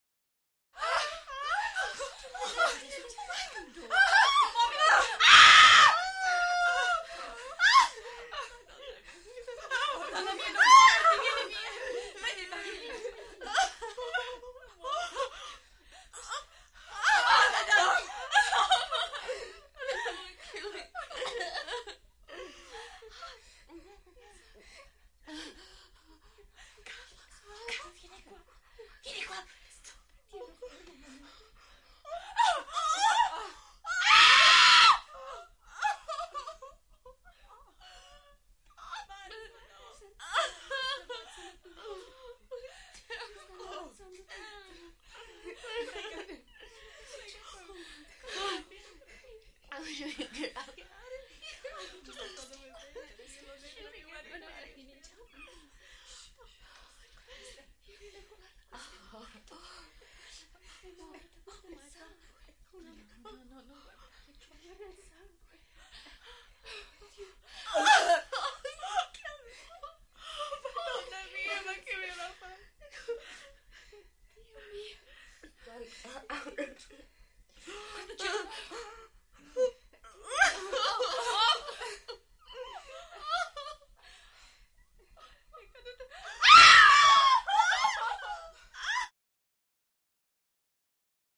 Interior Small Group Of Italian Women With Cries And Screams.